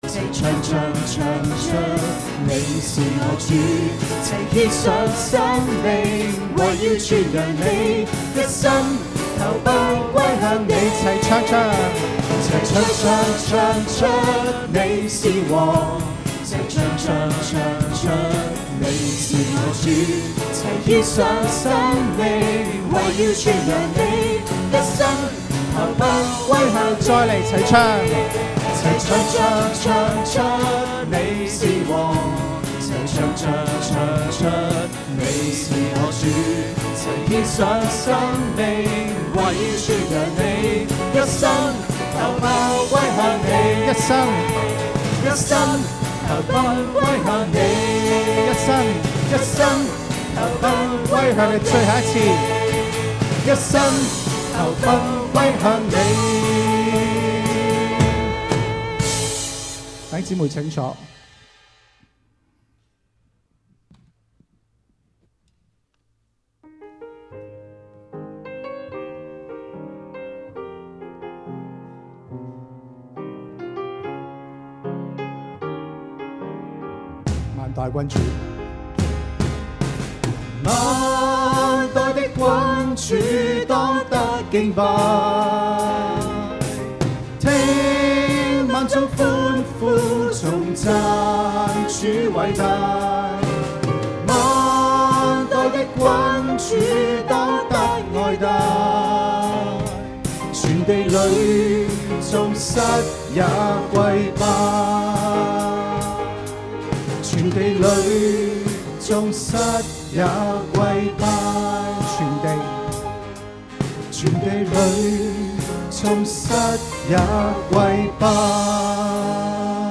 Chinese Sermon 中文講道